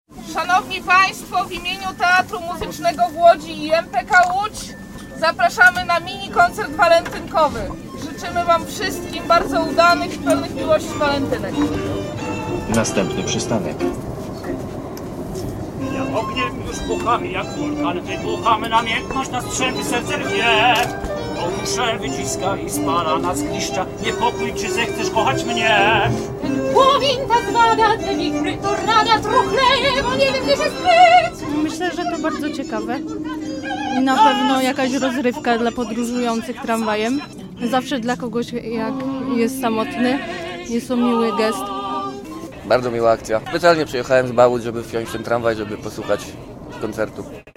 Na ulice Łodzi wyjechał "Tramwaj zwany pożądaniem". W południe w tramwaju linii 10 zaśpiewali soliści Teatru Muzycznego w Łodzi.
zaśpiewali miłosne duety ze słynnych operetek
Nazwa Plik Autor Walentynkowy koncert w tramwaju audio